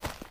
STEPS Dirt, Run 01.wav